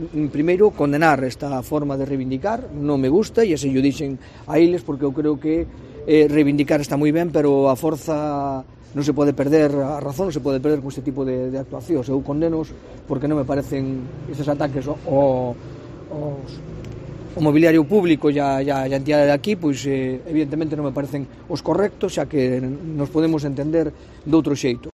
El presidente de la Diputación se pronuncia sobre la manifestación de Bomberos ante el Pazo